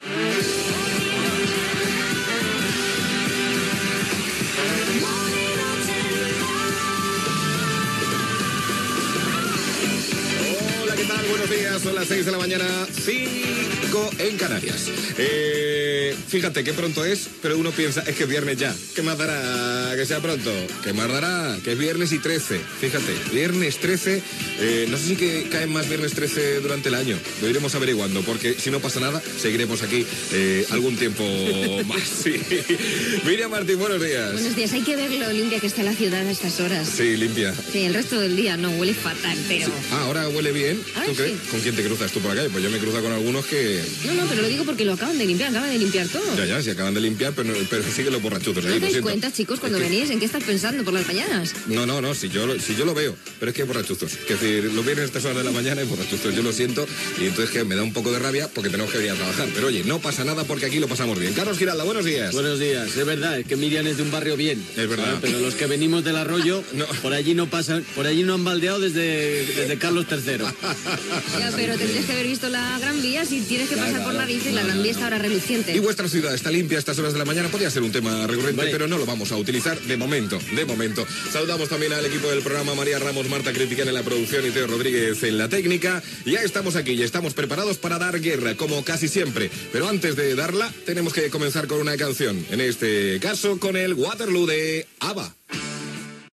Careta del programa, hora, data, salutació a l'equip, tema musical
Entreteniment